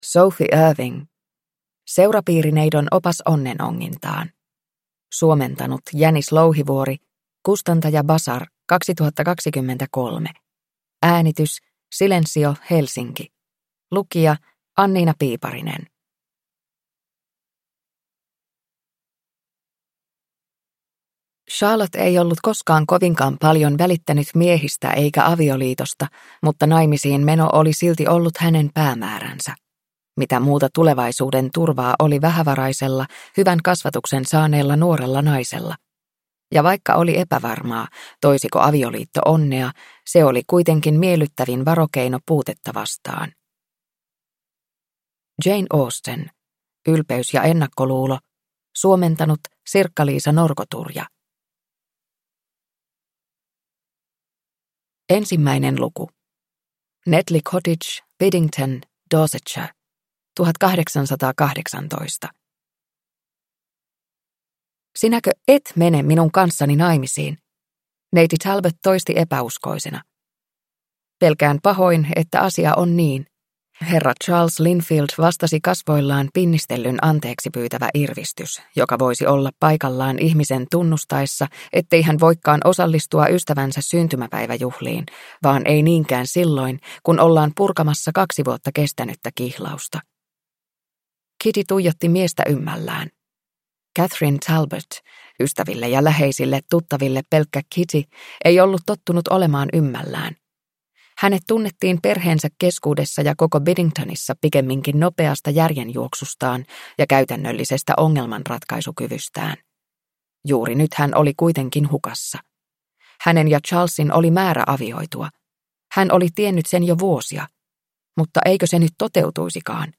Seurapiirineidon opas onnenongintaan – Ljudbok – Laddas ner